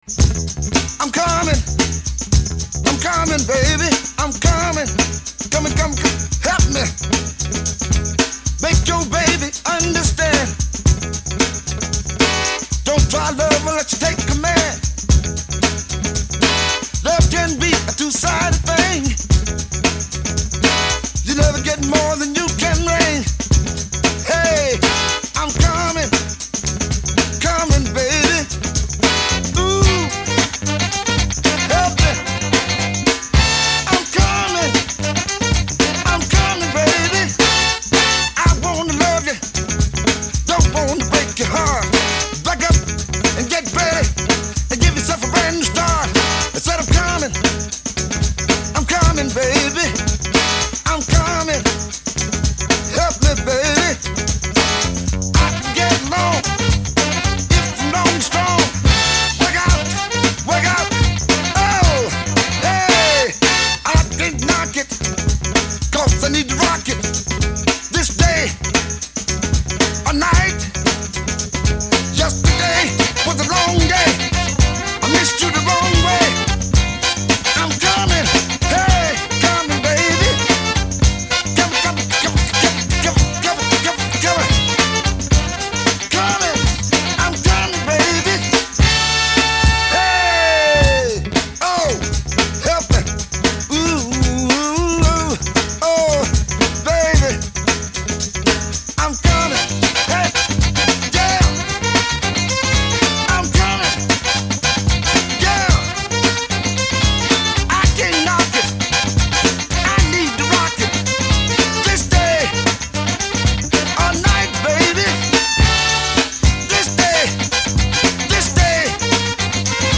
Here are those constituent drum parts